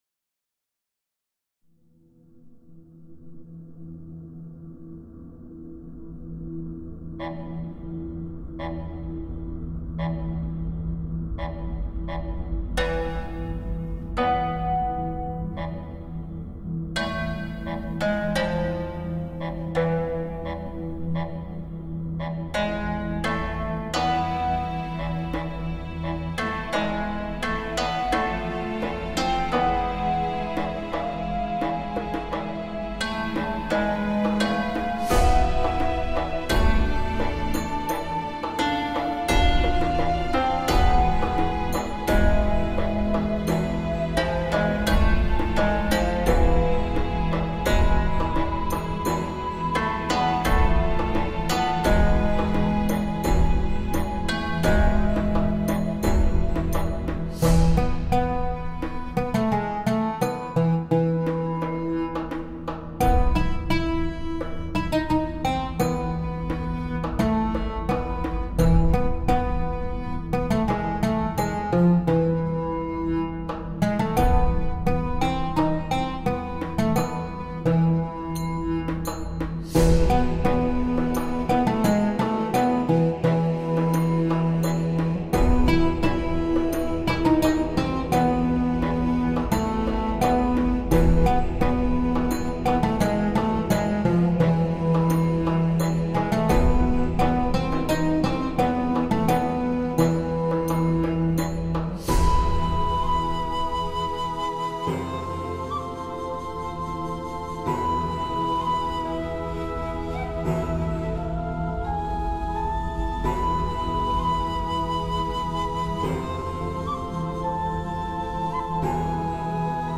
MGzrdNYHLfo_Ancient-Arabian-Music---Lost-City-of-the-Sands.mp3